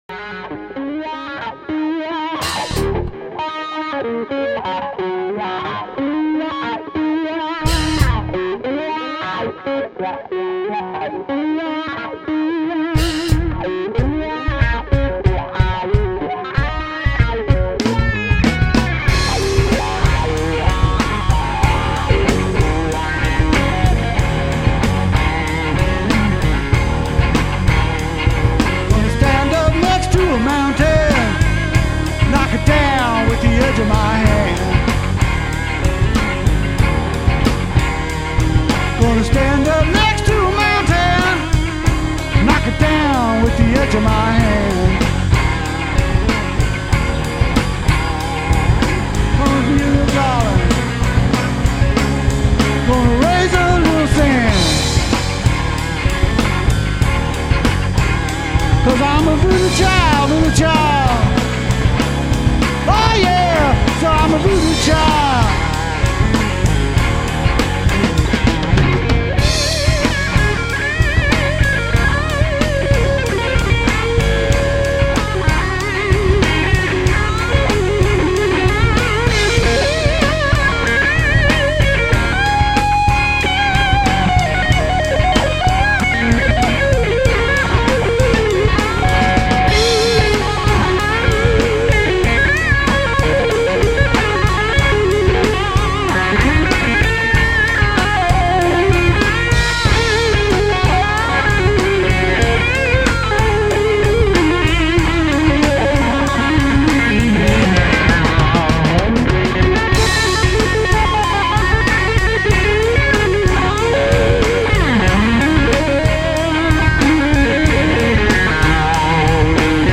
Vocals and Drums
Guitar Keyboard Pedal